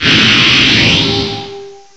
cry_not_buzzwole.aif